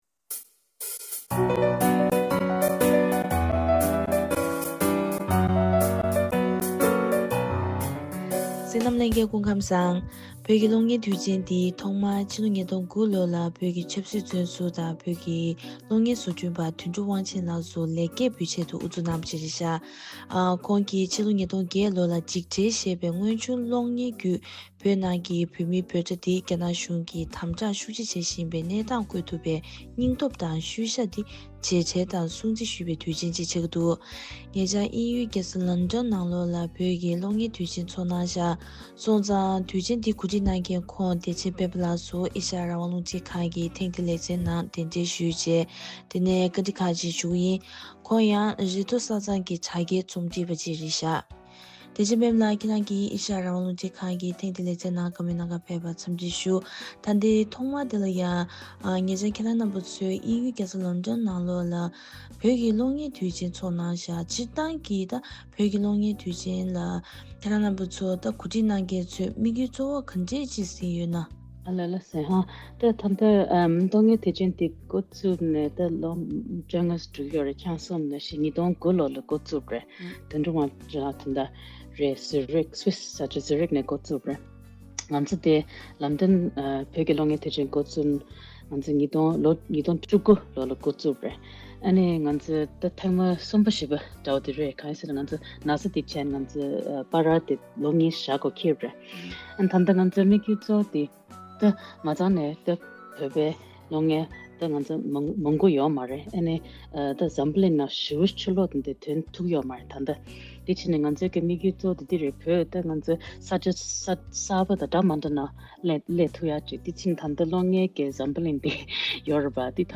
བཀའ་འདྲི་ཞུས་པའི་ལེ་ཚན་འདི་གཤམ་ལ་གསན་གནང་གི་རེད།